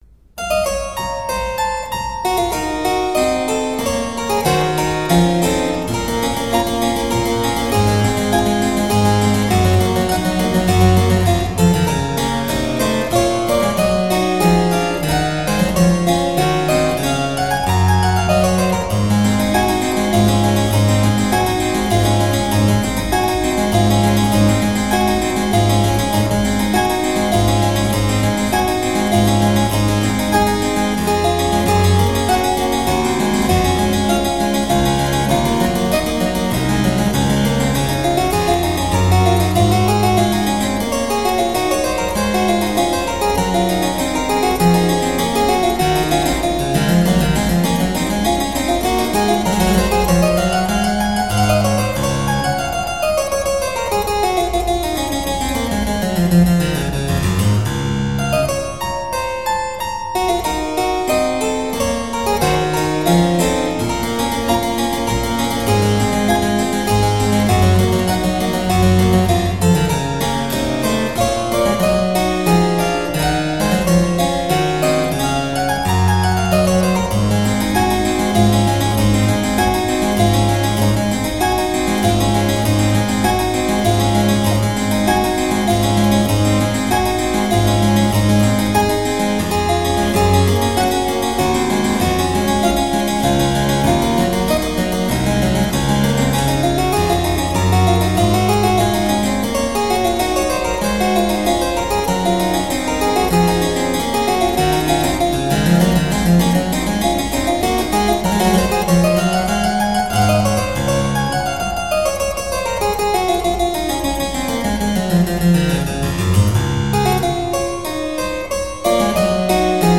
Sonate pour clavecin Kk 27 : Allegro